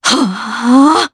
Isolet-Vox_Casting5_jp.wav